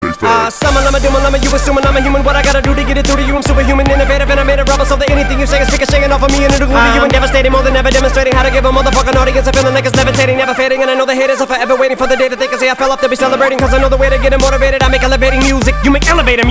• Качество: 320, Stereo
Хип-хоп
быстрые
речитатив
четкий рэп